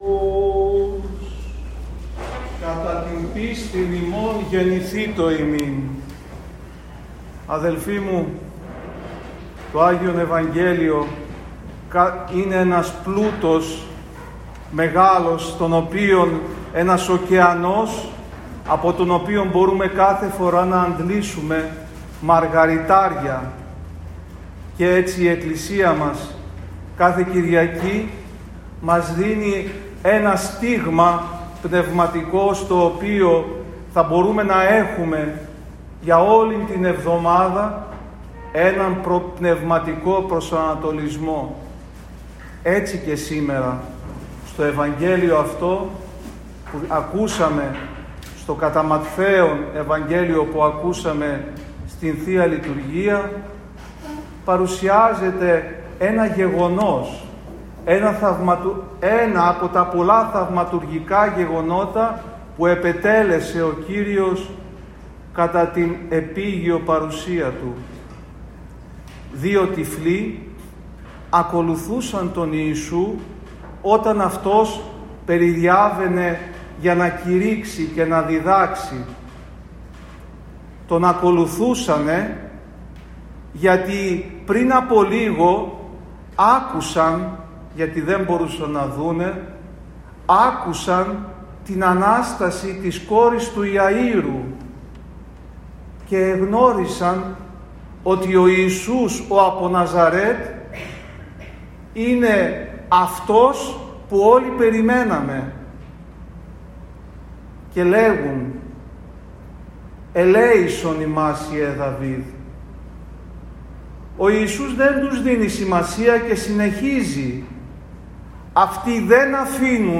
Ὁμιλία